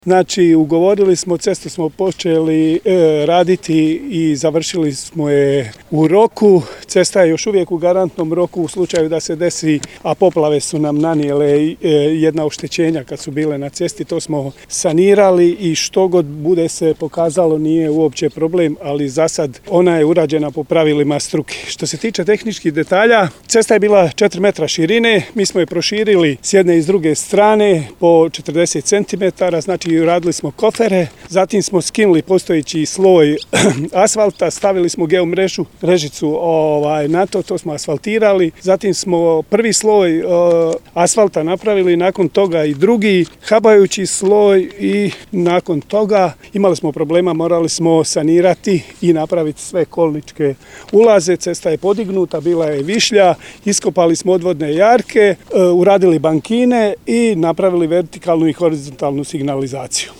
FOTO | Svečano otvorena cesta Mala Ludina-Kompator-Mustafina Klada